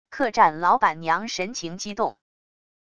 客栈老板娘神情激动wav音频